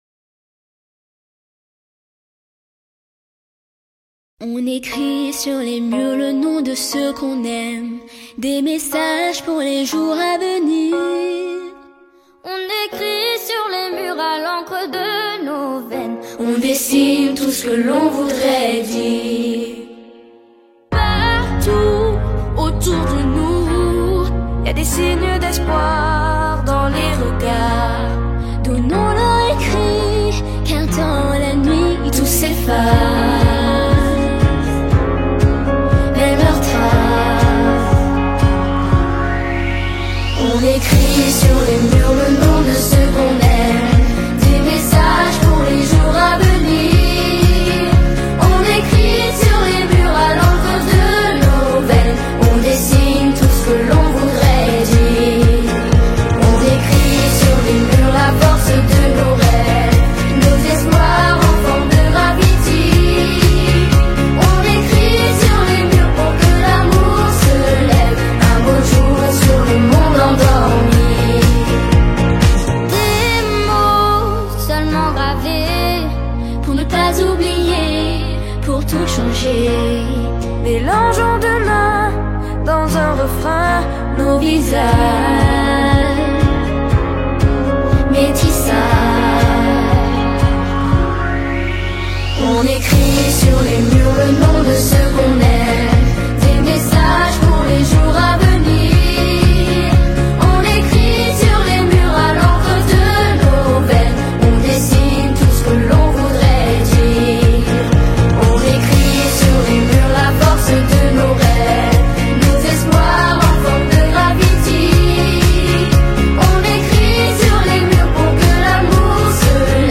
Version chantée :